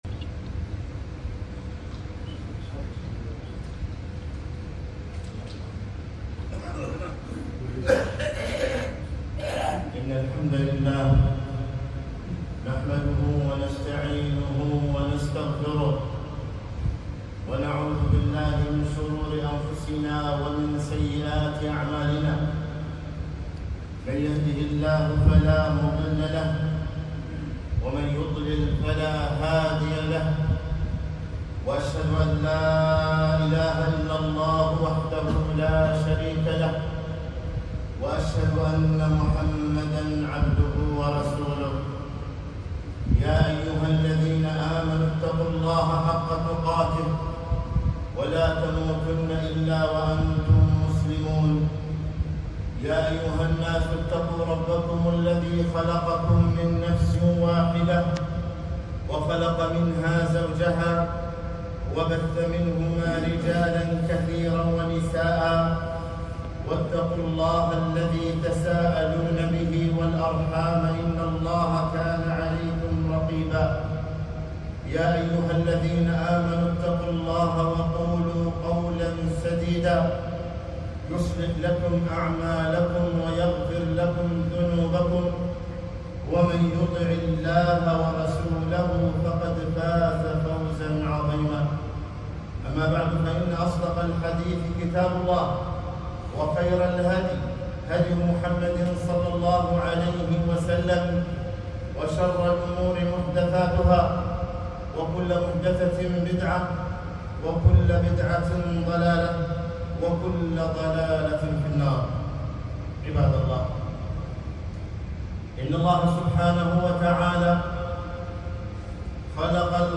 خطبة - التوحيد التوحيد